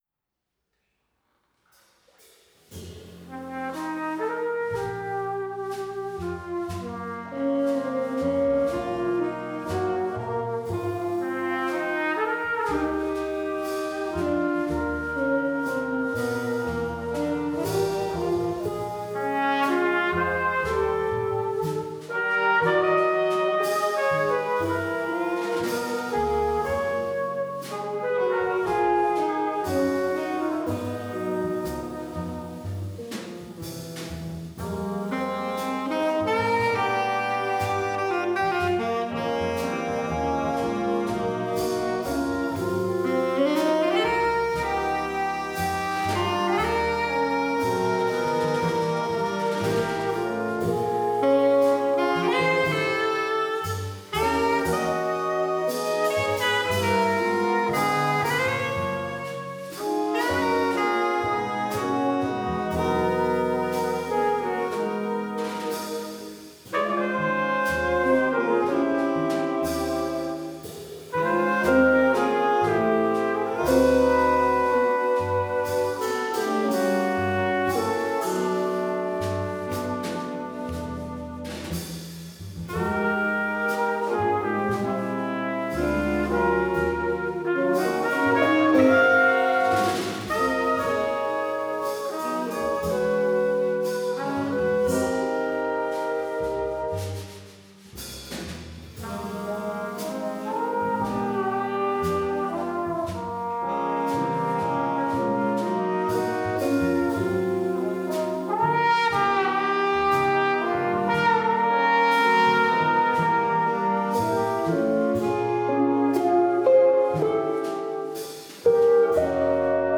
JAZZ_110105_koncert_pedagogů jazz_110105_koncert_pedagogu/10